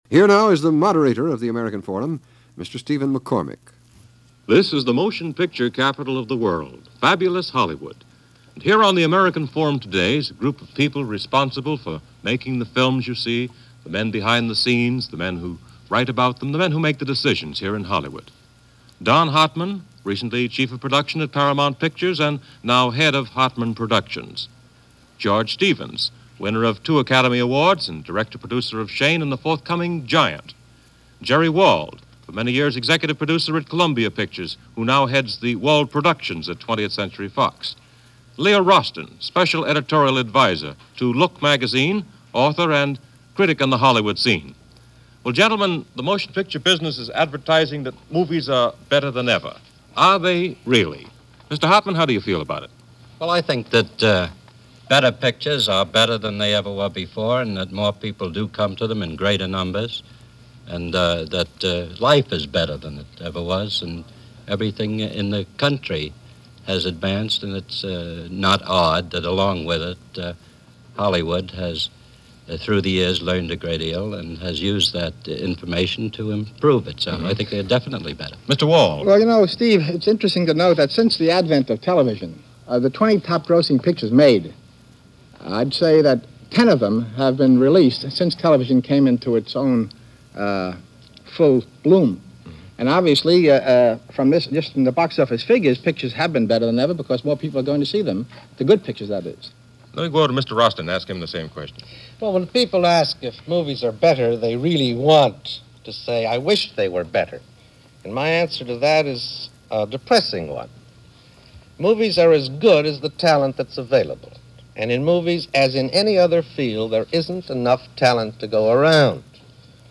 American Film In 1956 - George Stevens, Jerry Wald And Leo Rosten Discuss The State Of Hollywood Movies - Past Daily Weekend Gallimaufry
And this half-hour discussion program offers a number of interesting and illuminating theories on what the state of Hollywood was, some 66 years ago.